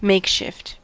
Ääntäminen
IPA : /ˈmeɪkˌʃɪft/